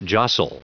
Prononciation du mot jostle en anglais (fichier audio)
Prononciation du mot : jostle